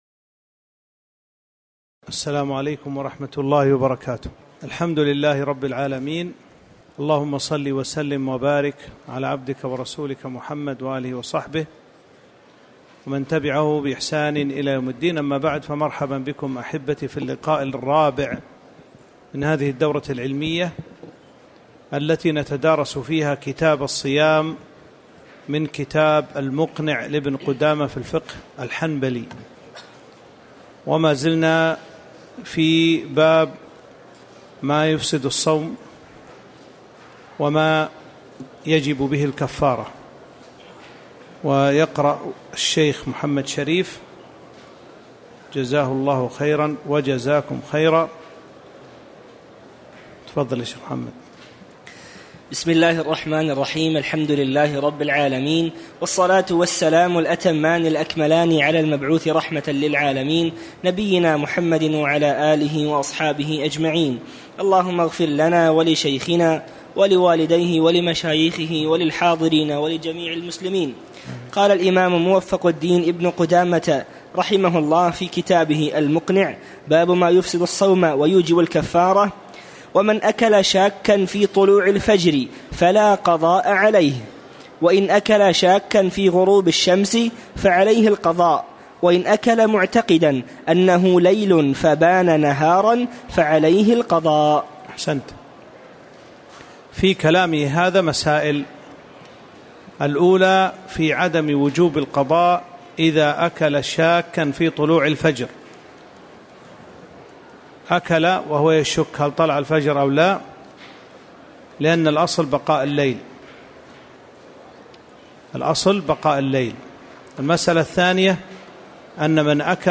تاريخ النشر ٢٤ شعبان ١٤٤٥ هـ المكان: المسجد النبوي الشيخ